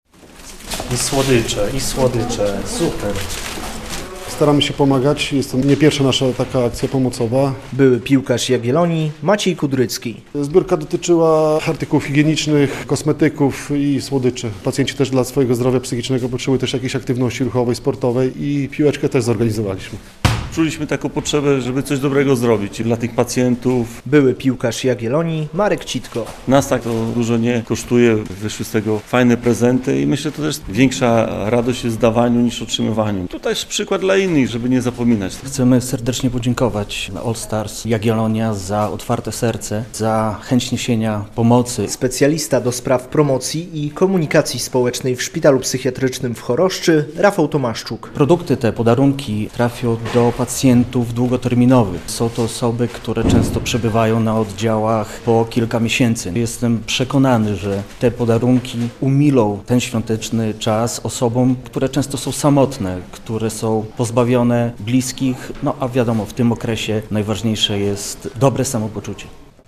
relacja
były piłkarz Jagiellonii, obecnie zawodnik Allstars Jagiellonia